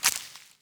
harvest_2.wav